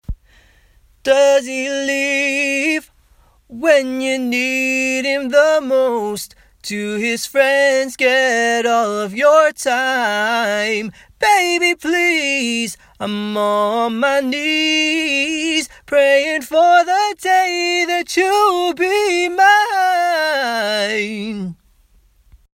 I found the lows to be lacking somewhat if this take is raw.
There’s no EQ on it, that’s raw signal.